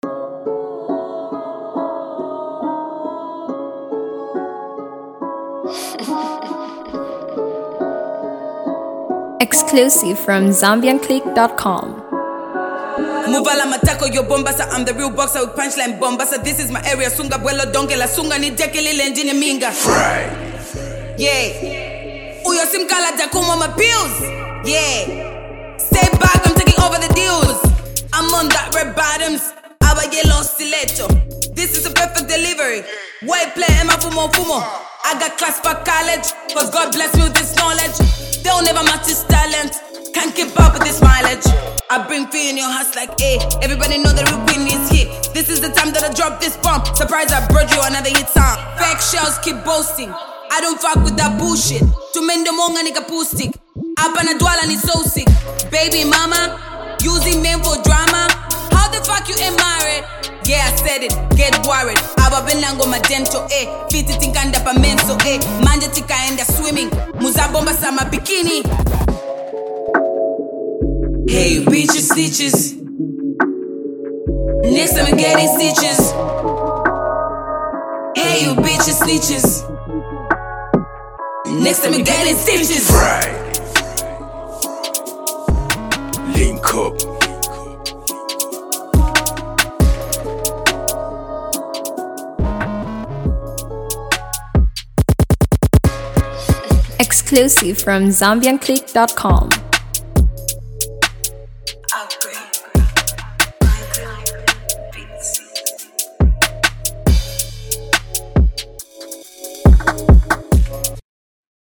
Zambian female rapper
freestyle song
drill beat with a heavy 808.